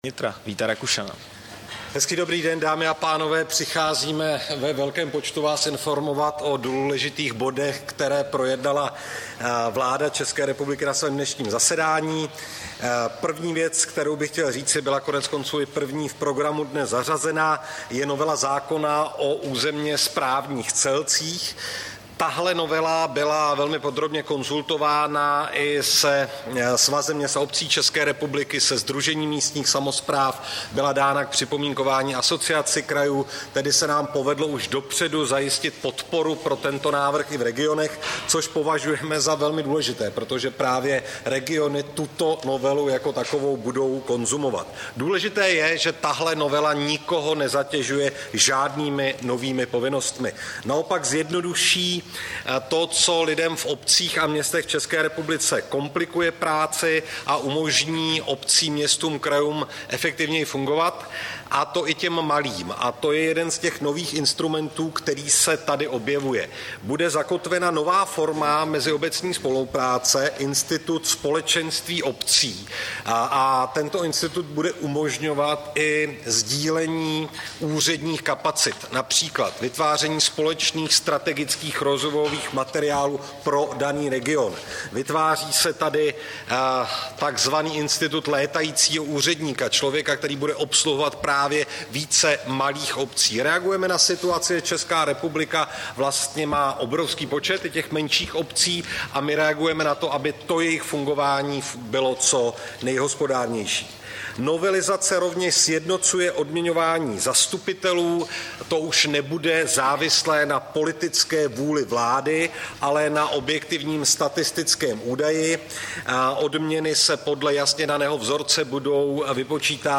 Tisková konference po jednání vlády, 12. července 2023